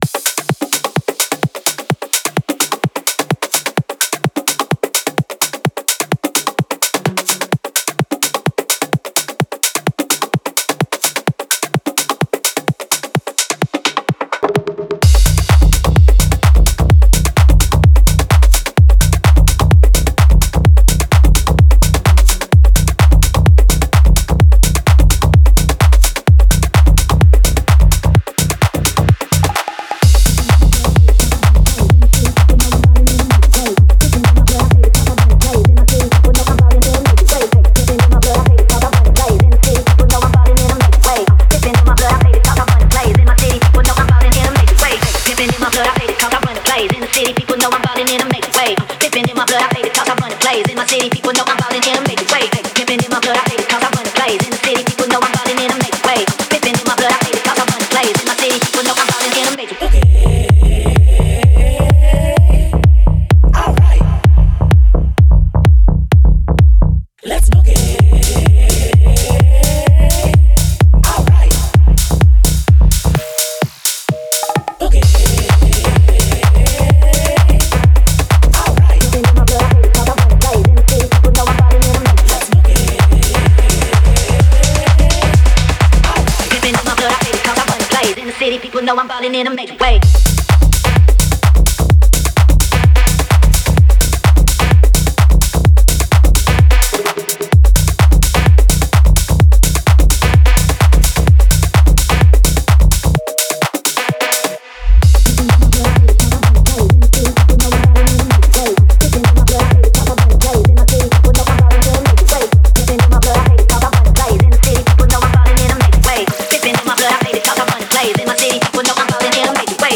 Type: Serum Samples
Electro House Future House Hardcore / Hardstyle House Melodic Techno Phonk house Progressive House Techno Trap Tropical House
Explore punchy drums, infectious basslines, mesmerizing synths, and atmospheric soundscapes. Shape your sounds with presets for virtual synths.